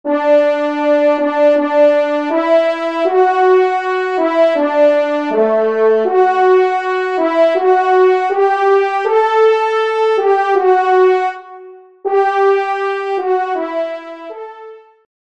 Genre : Fantaisie Liturgique pour quatre trompes
Pupitre 1° Trompe